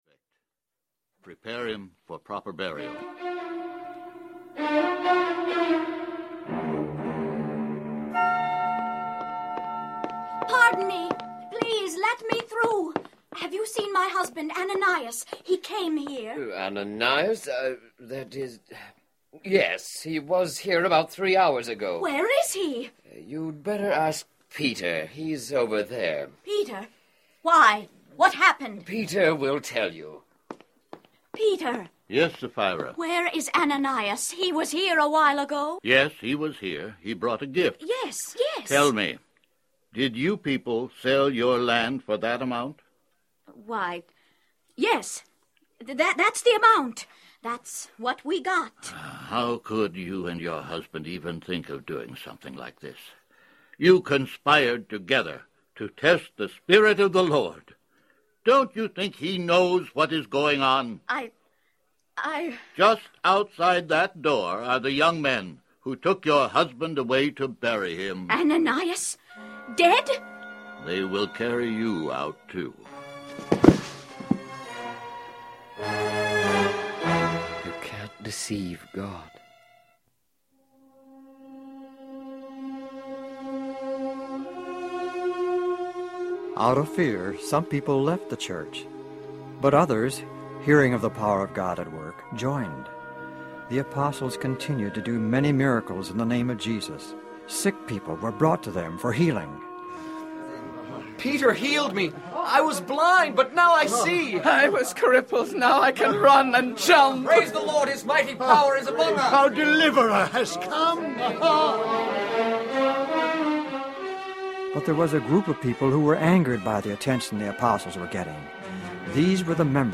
Acts of the Apostles Audiobook
This volume contains 16 dramatized, audio, Bible stories from the New Testament about the early Church and brings the Pentacost, Peter, James, John and the life of Paul and his missionary journeys “to life.”
6.6 Hrs. – Unabridged
Acts of the Apostles Your Story Hour SAMPLE.mp3